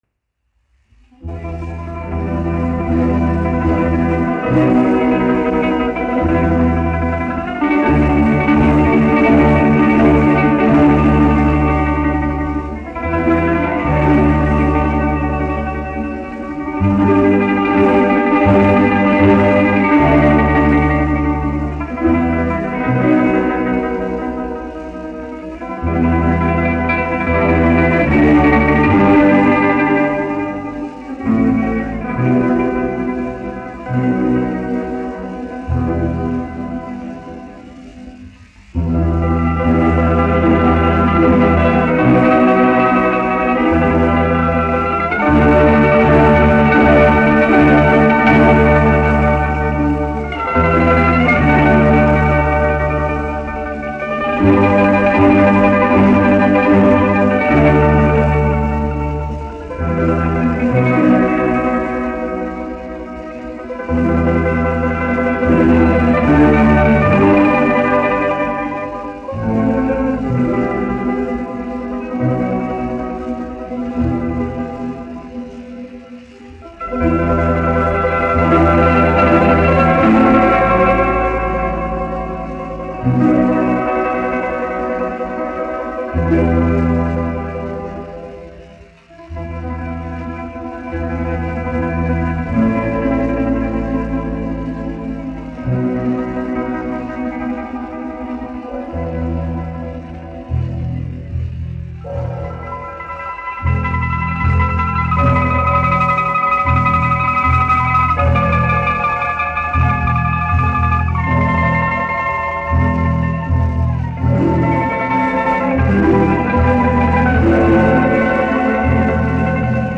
1 skpl. : analogs, 78 apgr/min, mono ; 25 cm
Balalaiku orķestra mūzika, aranžējumi
Skaņuplate